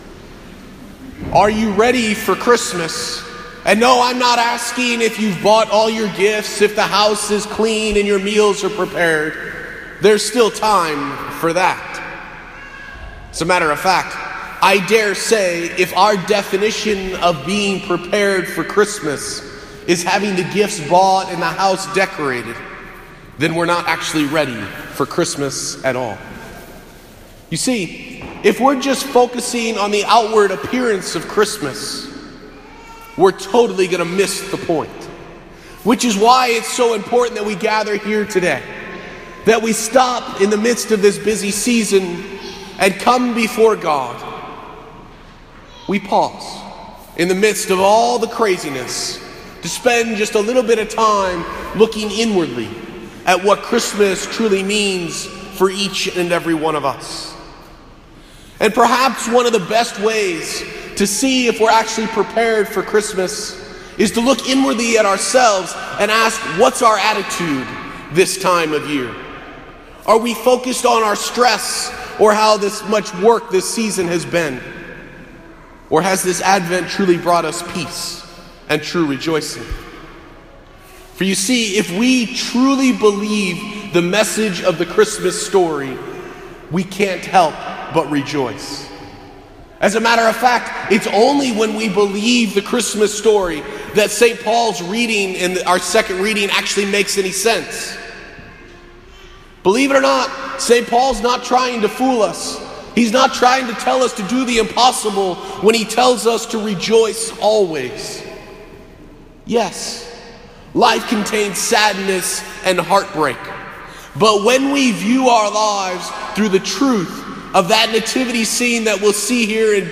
Posted in Homily